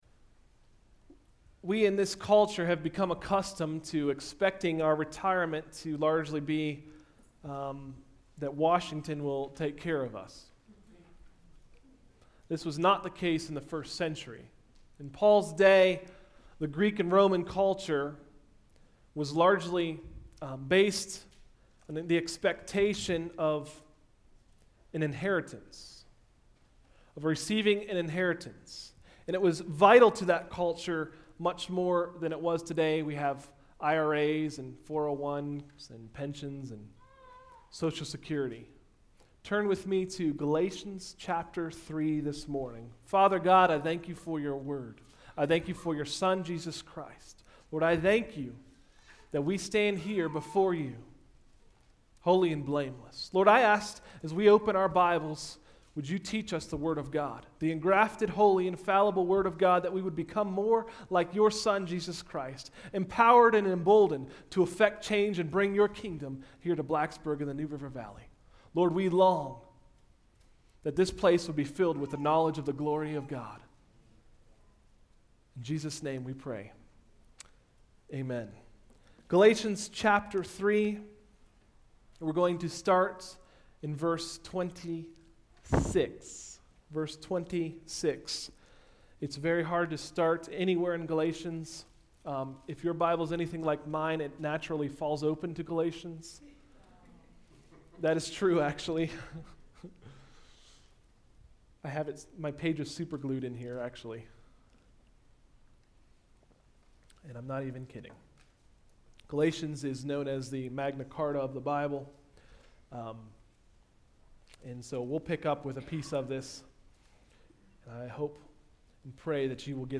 Sermons: “Sonship in Christ”